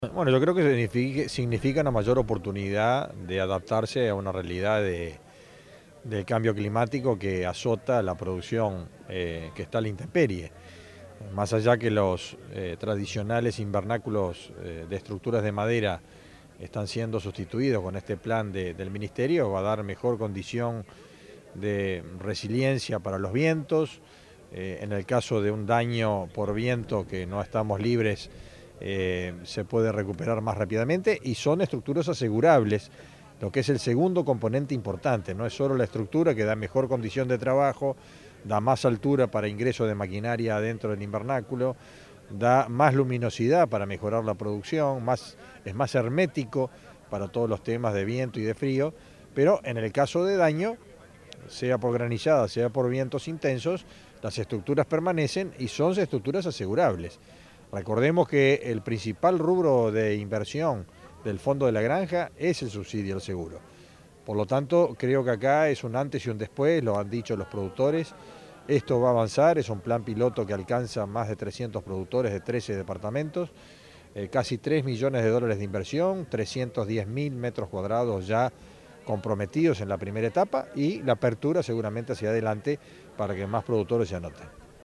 Escuchar a Fernando Mattos: